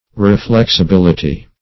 Search Result for " reflexibility" : The Collaborative International Dictionary of English v.0.48: Reflexibility \Re*flex`i*bil"i*ty\ (r?*fl?ks`?*b?l"?*t?), n. [Cf. F. r['e]flexibilit['e].] The quality or capability of being reflexible; as, the reflexibility of the rays of light.